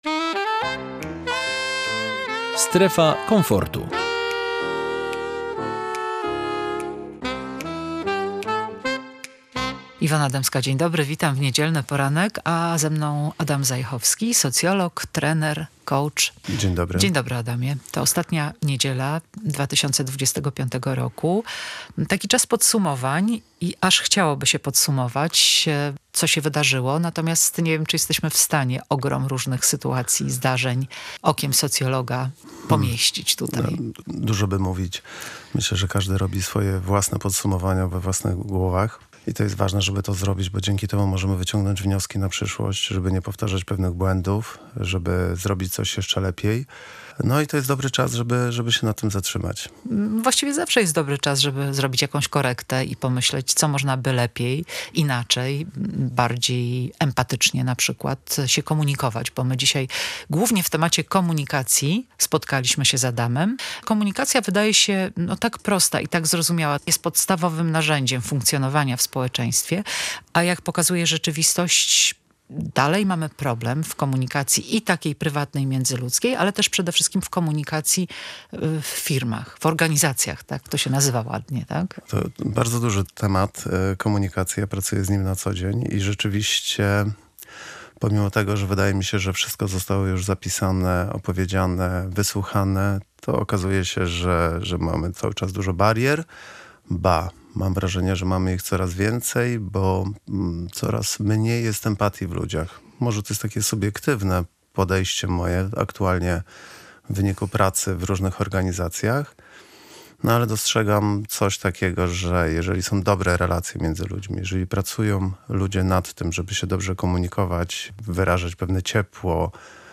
socjologiem i szkoleniowcem komunikacji.